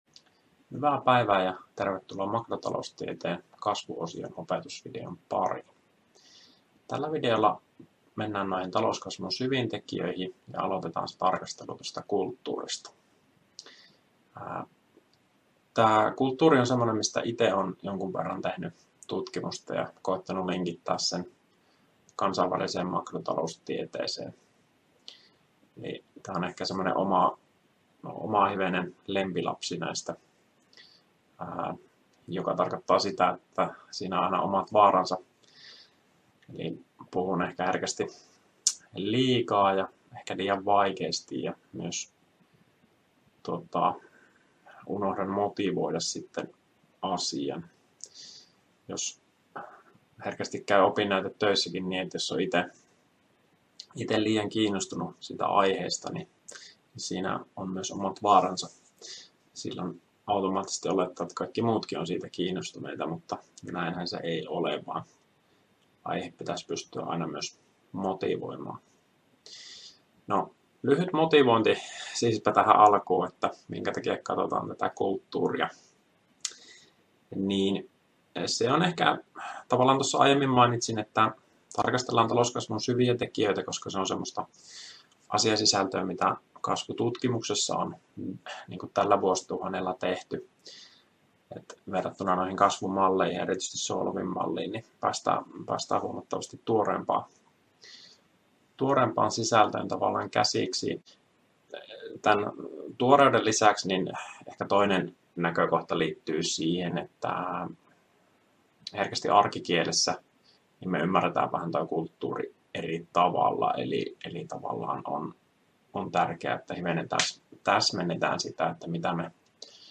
Opintojakson "Makrotaloustiede I" kasvuosion 8. opetusvideo